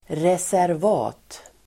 Ladda ner uttalet
Uttal: [resärv'a:t]